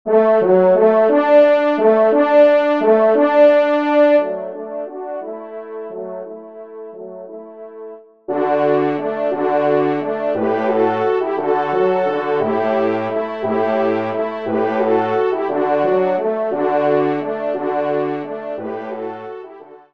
Genre :  Divertissement pour Trompes ou Cors
4e Trompe